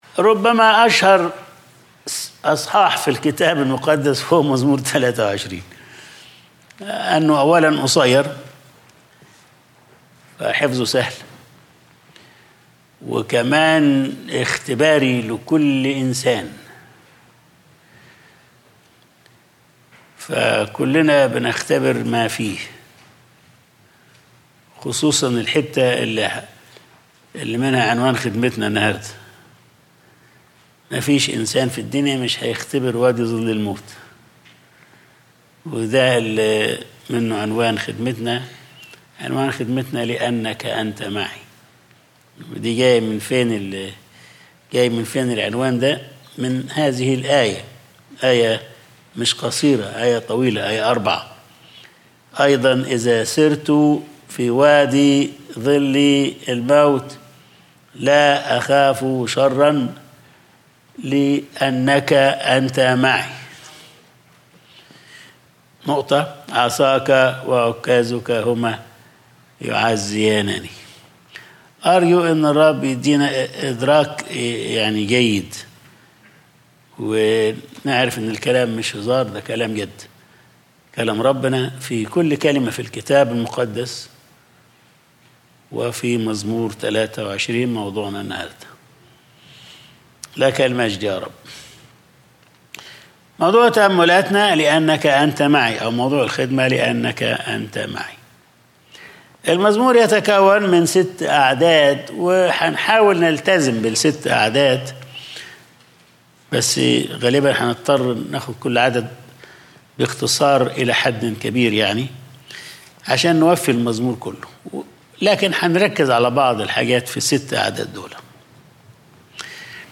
Sunday Service | لأنك أنت معي